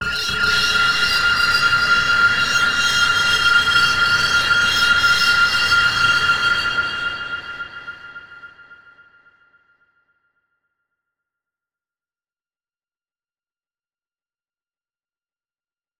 Here’s a test with the same 3 sounds on both hardware and software, all recorded into Digitakt II.
Its still got the same overall character as the hardware, but there’s clearly some differences in the software version, probably partly due to the updated effects (the software reverb actually doesnt glitch like the hardware does).